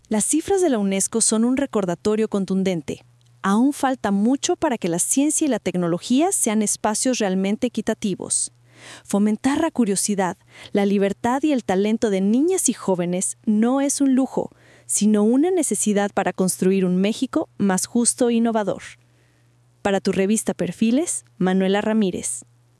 Comentario editorial (20 segundos):